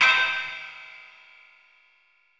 Index of /90_sSampleCDs/AMG - Prototype Drum & Bass/REX Files/Mini Tracks/Jump Up
Jump Up Skank.WAV